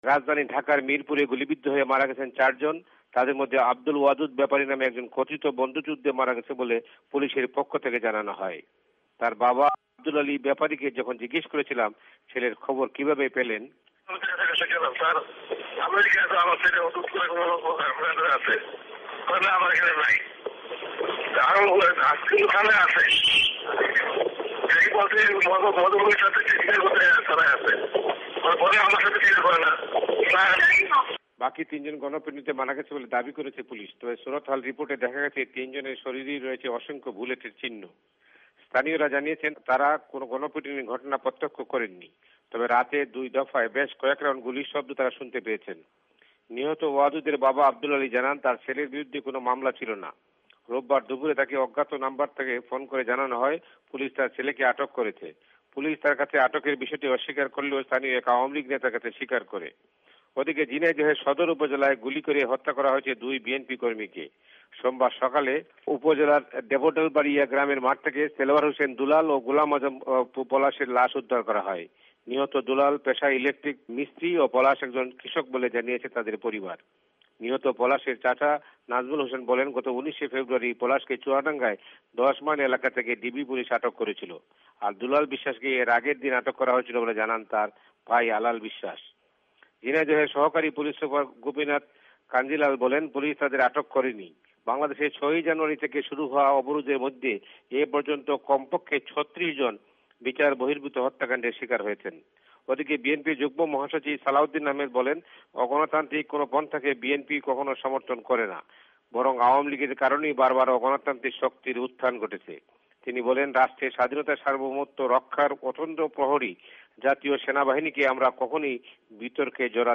ভয়েস অফ এ্যামেরিকার বাংলাদেশ সংবাদদাতাদের রিপোর্ট